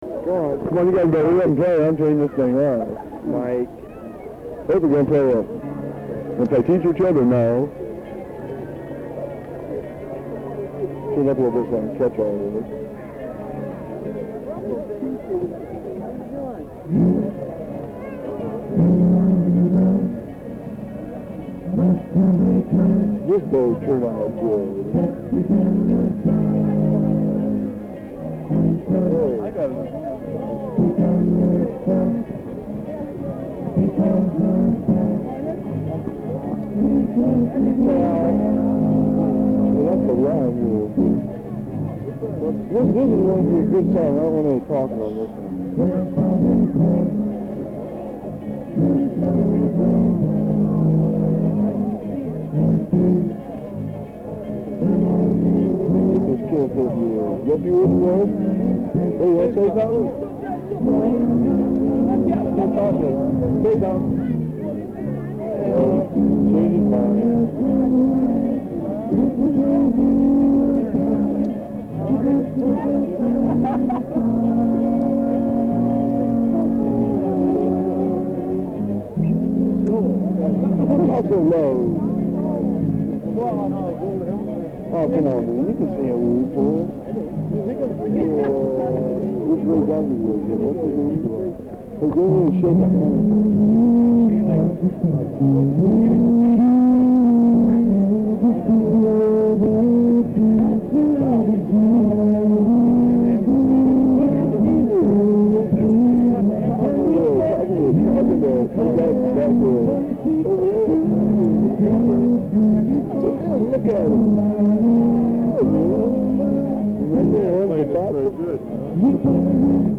This was an outdoor concert held on a Sunday afternoon in the summer of 1972 in Harrison Smith Park in Upper Sandusky, Ohio.
Disclaimer: The audio in the clips is of extremely poor quality! Plus my friends and I were talking throughout.